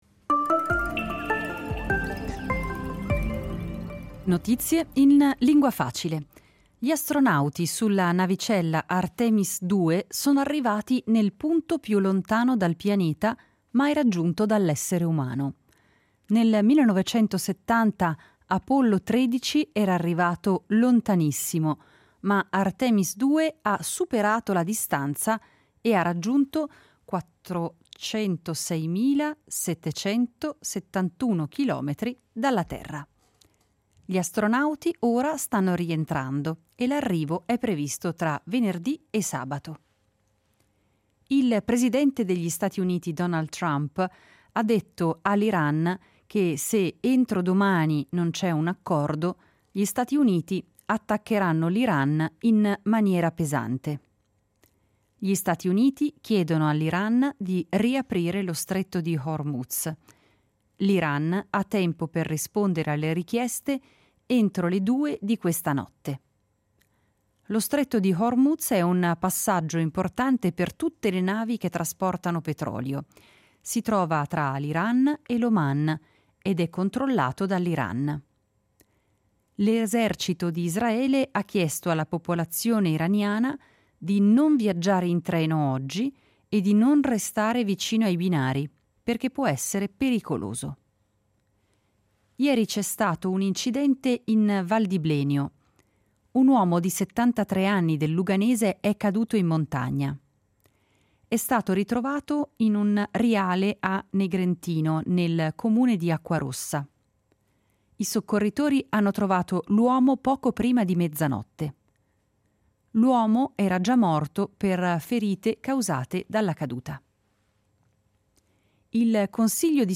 Notizie in lingua facile